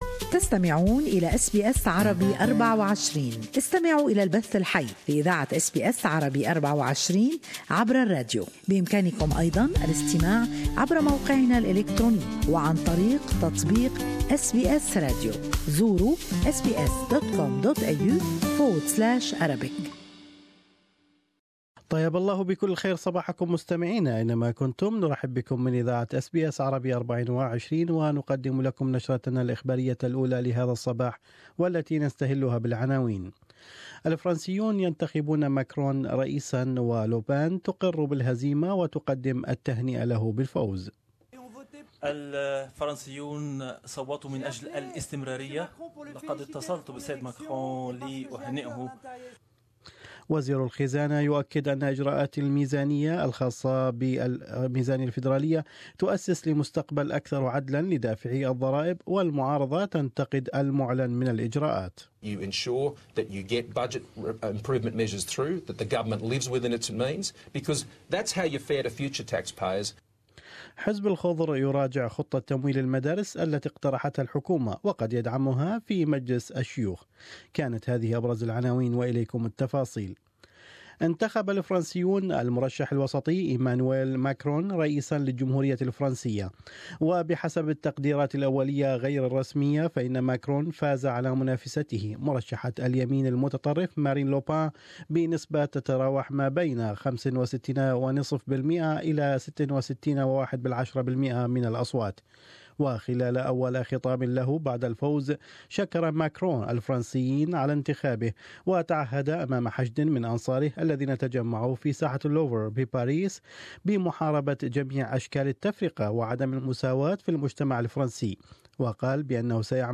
Australian and world news in news bulletin.